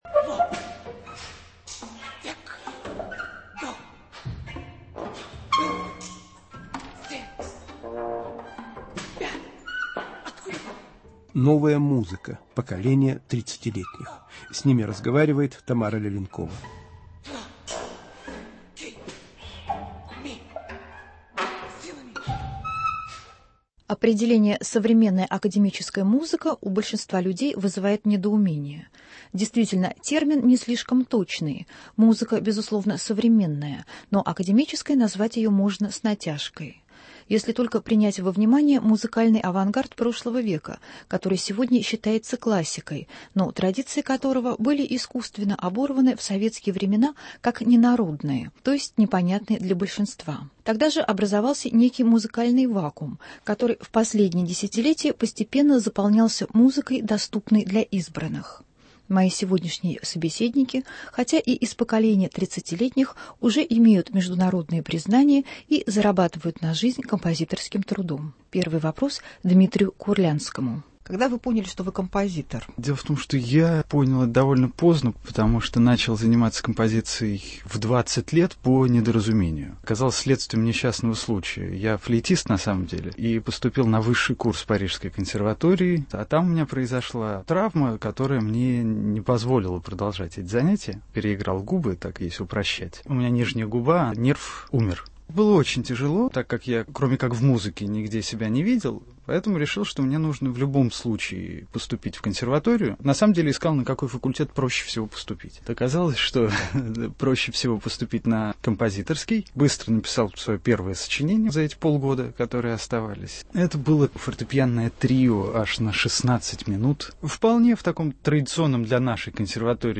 Тридцатилетние российские композиторы рассказывают о том, как они понимают традицию и авангард в музыке и знакомят со своими композициями